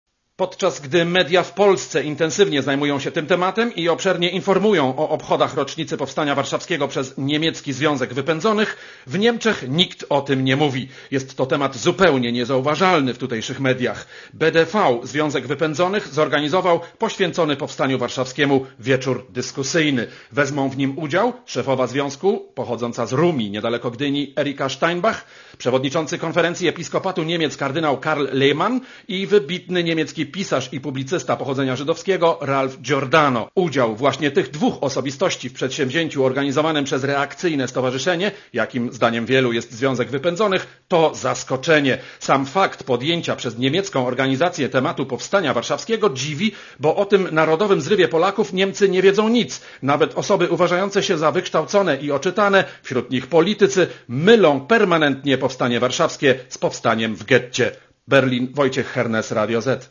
Korespondencja z Berlina